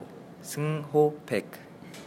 Seung-ho Paik kommt aus Südkorea. Doch wie wird der Name des neuen Darmstädter Spielers eigentlich ausgesprochen? Er hat es uns ins Mikrofon gesagt.